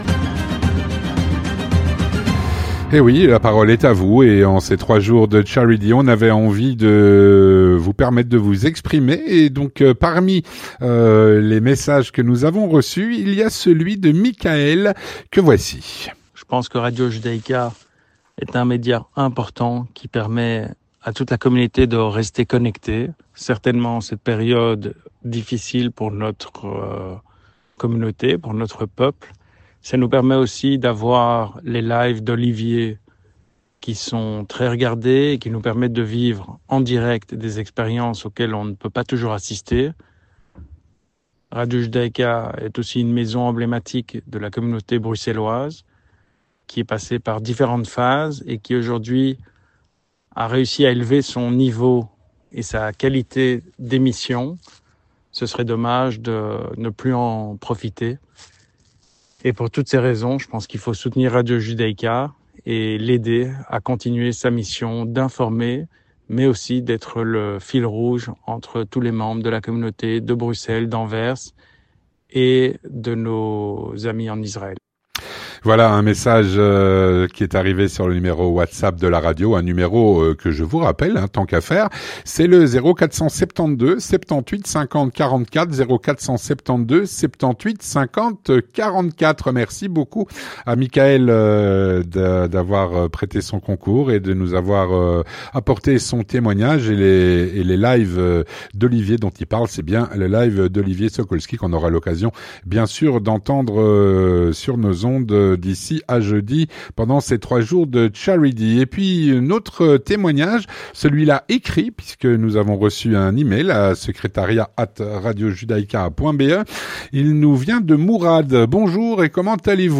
Dans "La parole est à vous", voici les témoignages de 3 auditeurs à l'occasion du "Caridy" de Radio Judaïca.